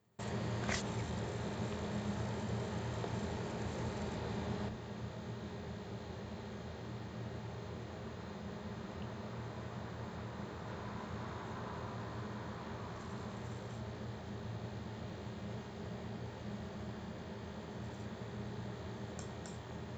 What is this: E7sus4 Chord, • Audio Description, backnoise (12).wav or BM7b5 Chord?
backnoise (12).wav